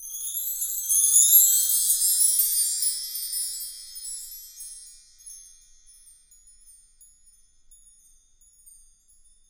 Index of /90_sSampleCDs/Roland LCDP03 Orchestral Perc/PRC_Wind Chimes1/PRC_W.Chime Up